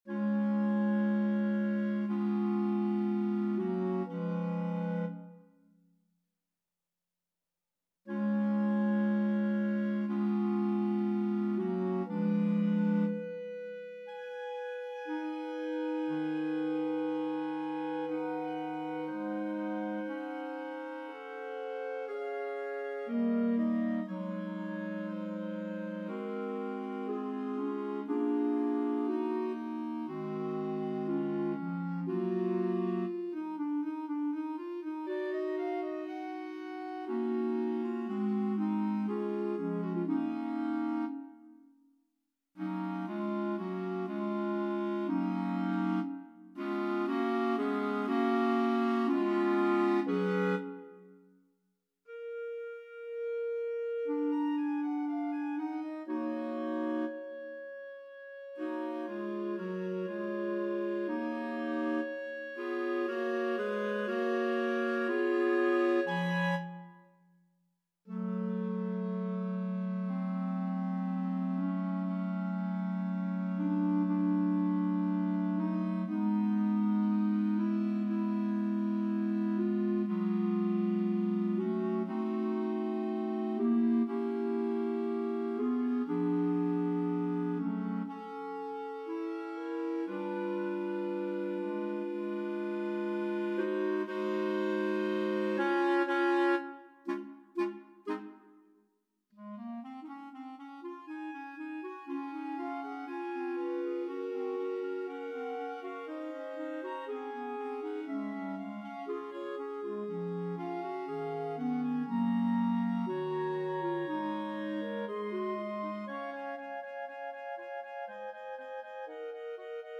Voicing: Bb Clarinet Quartet